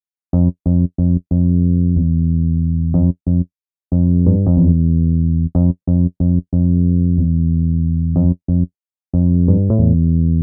描述：我正在做的一个嘻哈节拍的原声低音，我给它的唯一真正的嘻哈元素是鼓，真的。
Tag: 音响 低音 平静 寒意 免费 吉他 环路 钢琴